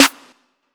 SNAPPY.wav